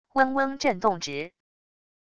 嗡嗡震动直wav音频